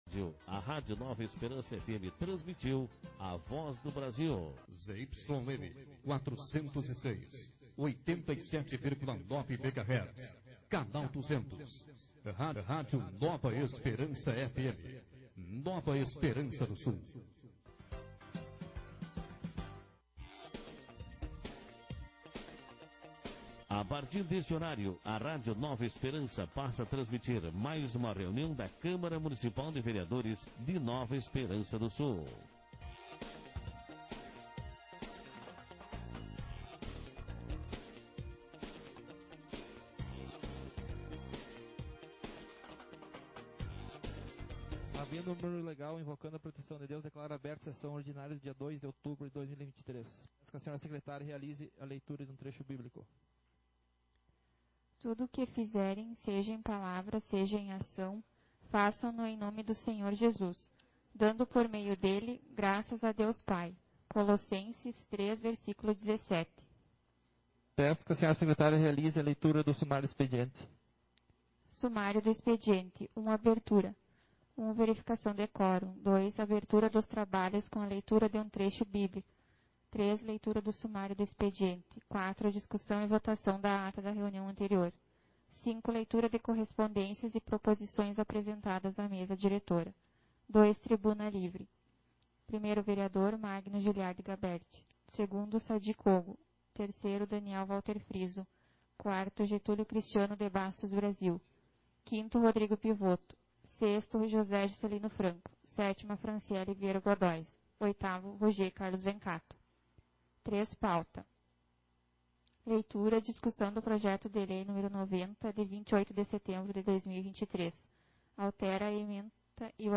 Sessão Ordinária 32/2023